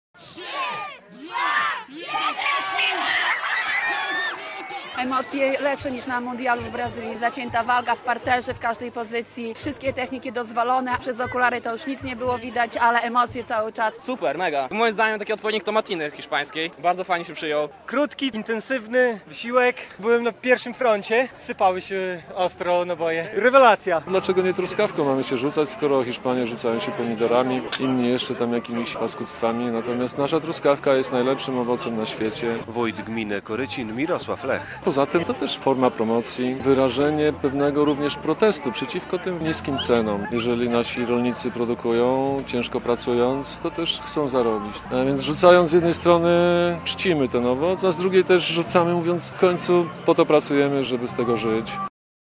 Bitwa truskawkowa w Korycinie - relacja